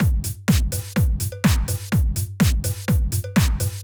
Drumloop 125bpm 08-A.wav